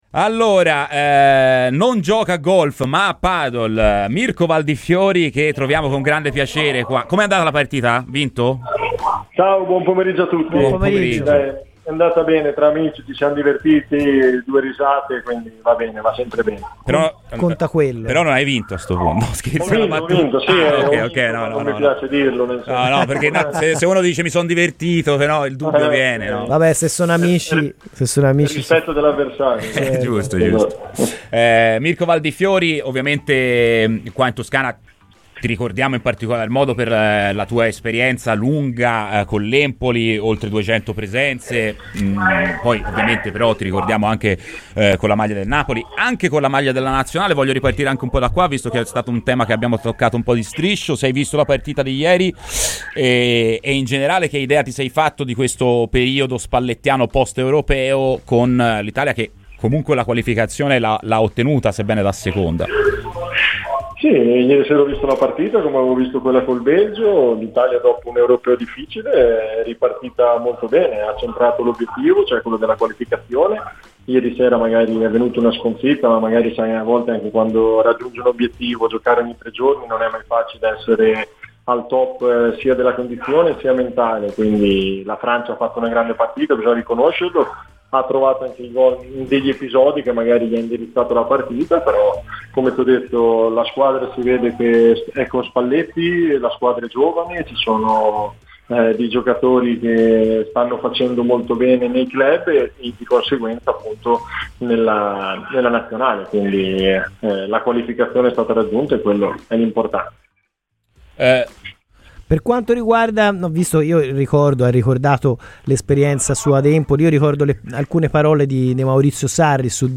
Le piace Adli?